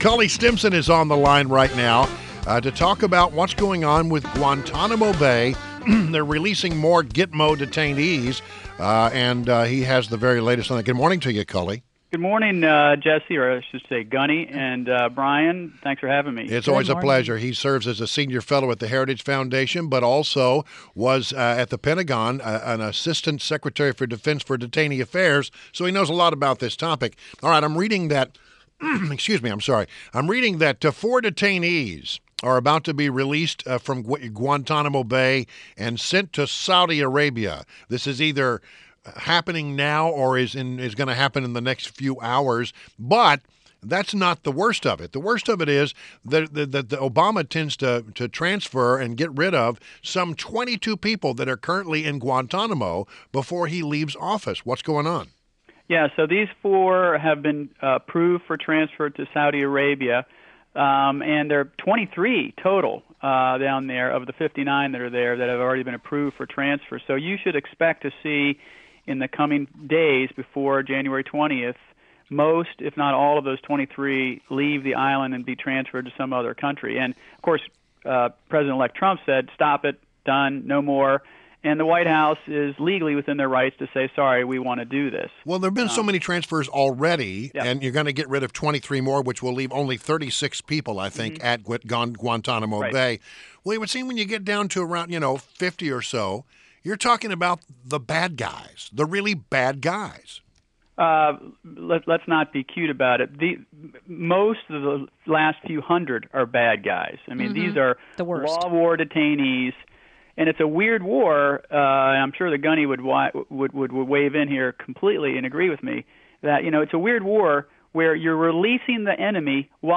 INTERVIEW: CULLY STIMSON – former Deputy Assistant Secretary of Defense for Detainee Affairs and Manager, National Security Law Program and Senior Legal Fellow at The Heritage Foundation